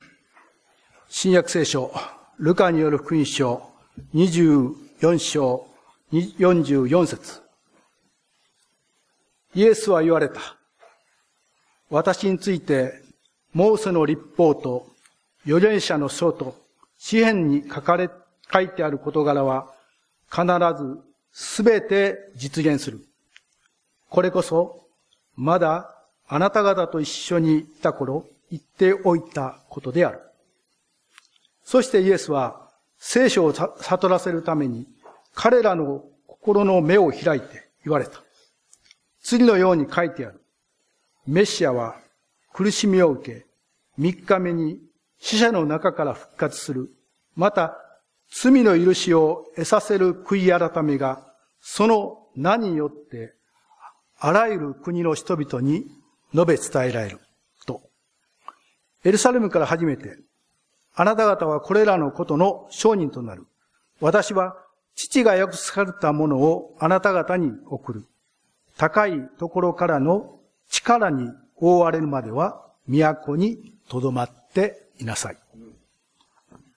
「大いなる委託」 宣 教